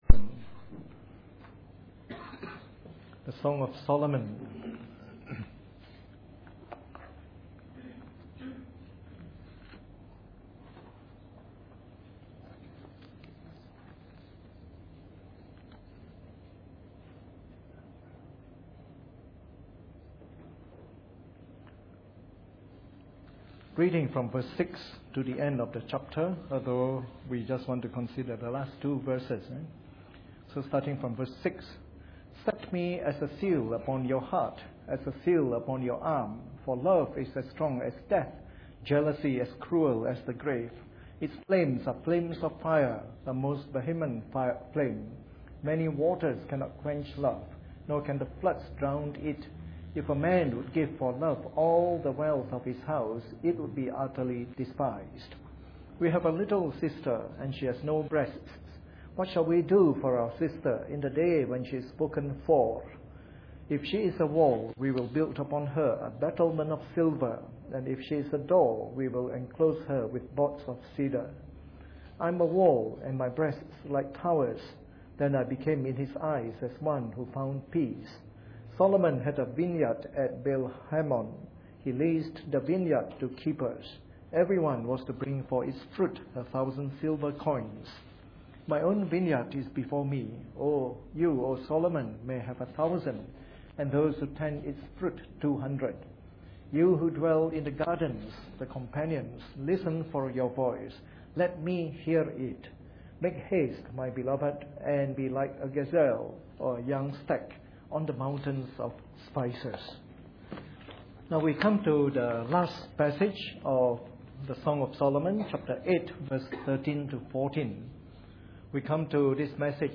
Preached on the 21st of March 2012 during the Bible Study from our series on the Song of Solomon.